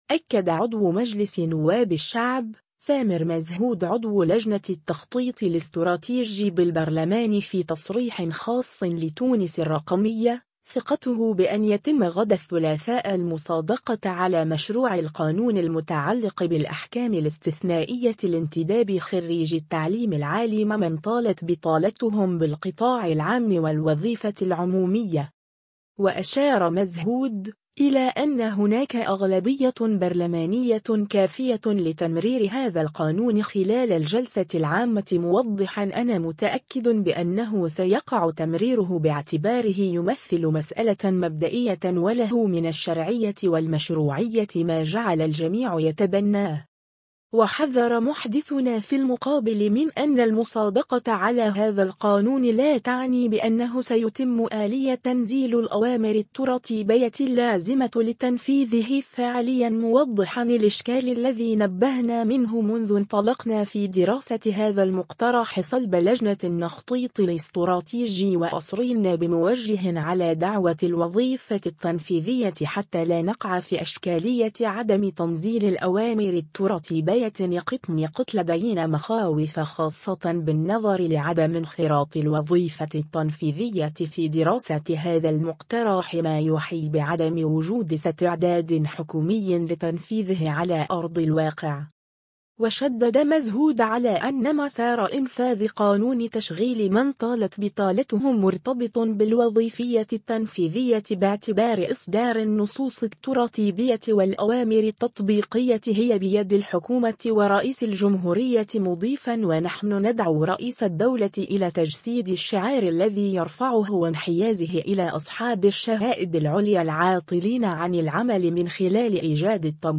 أكد عضو مجلس نواب الشعب،ثامر مزهود عضو لجنة التخطيط الاستراتيجي بالبرلمان في تصريح خاص لـ”تونس الرقمية”، ثقته بأن يتم غدا الثلاثاء المصادقة على مشروع القانون المتعلق بالأحكام الاستثنائية لانتداب خريجي التعليم العالي ممن طالت بطالتهم بالقطاع العام والوظيفة العمومية.